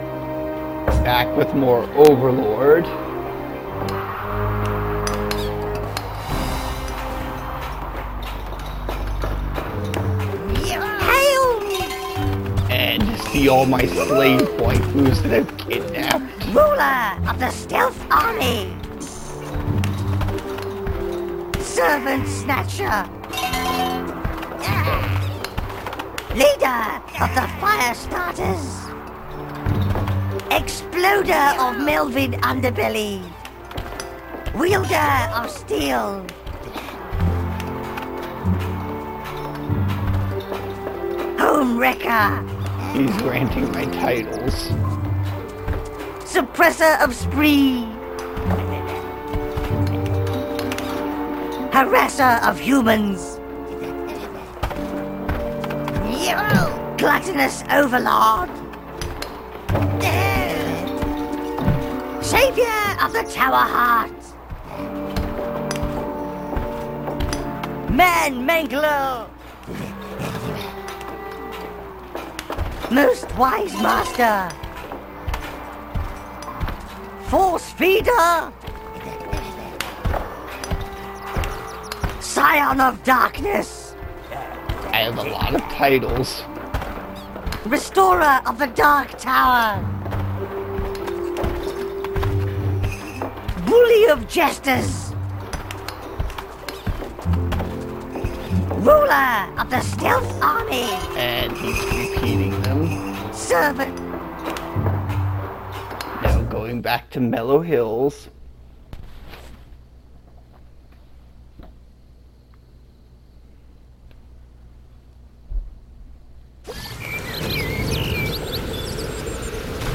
I play Overlord with commentary